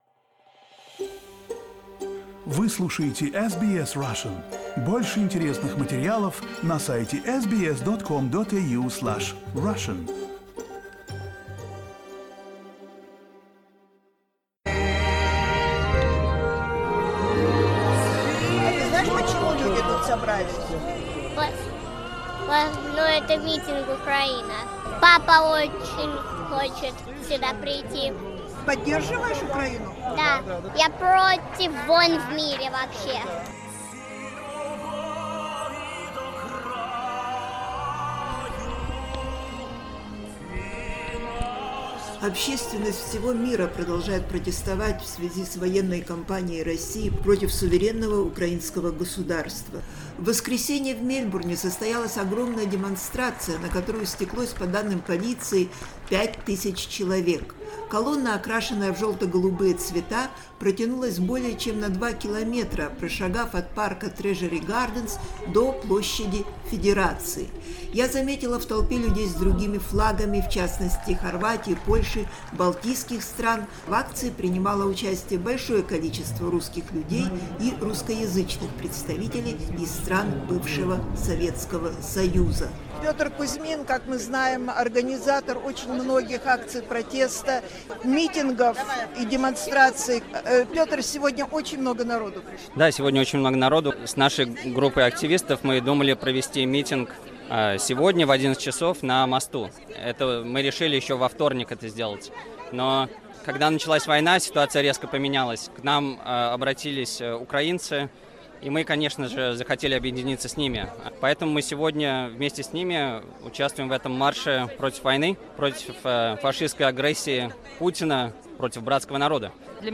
Репортаж с митинга в поддержку Украины и против войны, который сегодня прошел в центре Мельбурна.